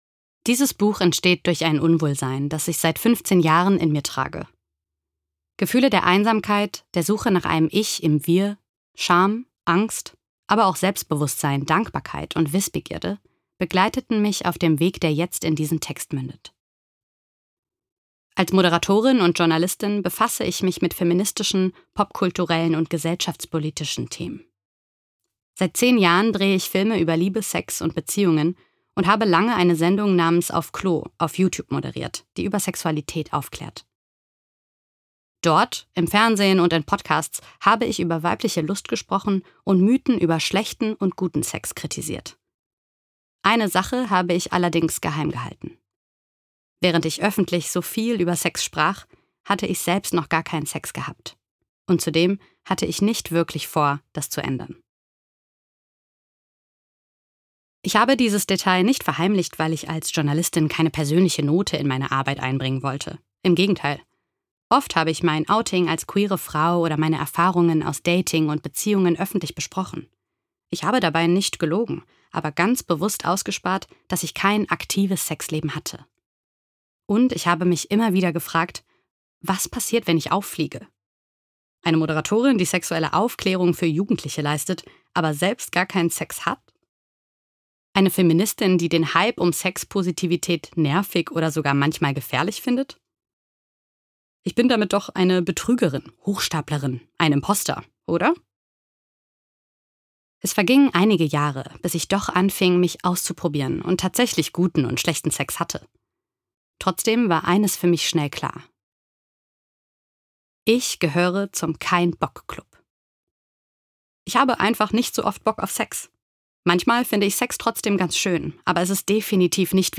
Cover Print Cover Web Hörprobe MP3